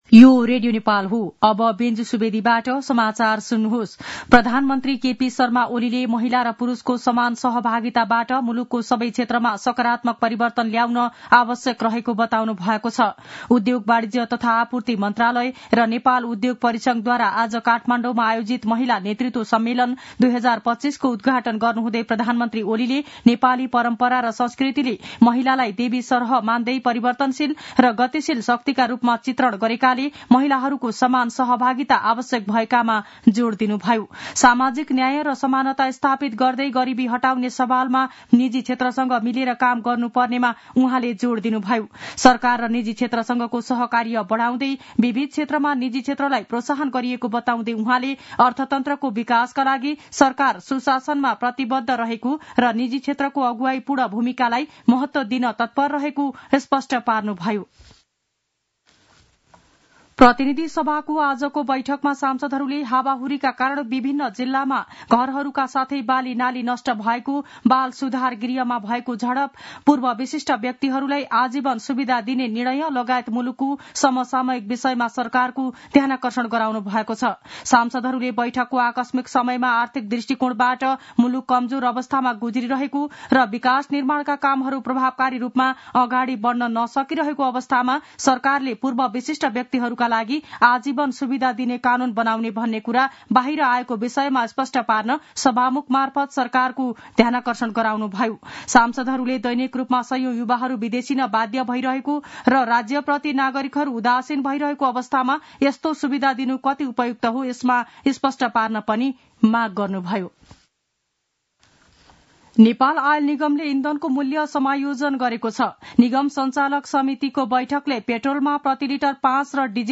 दिउँसो १ बजेको नेपाली समाचार : ३ चैत , २०८१